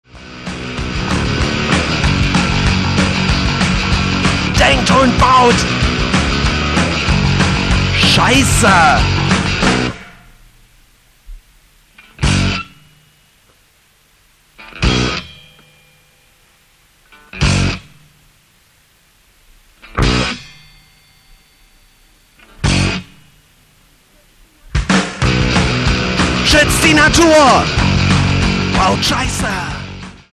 Disc 1 floors it with brutal punk shorts.